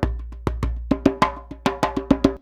100DJEMB21.wav